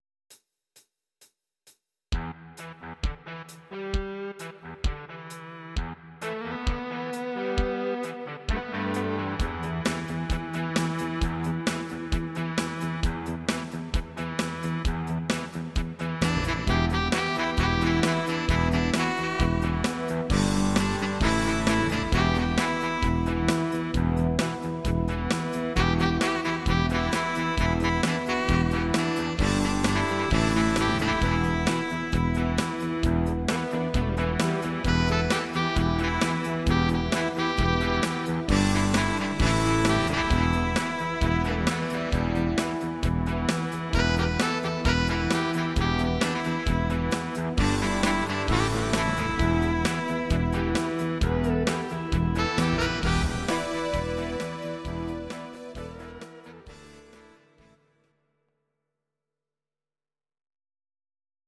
These are MP3 versions of our MIDI file catalogue.
Please note: no vocals and no karaoke included.
Your-Mix: Instrumental (2060)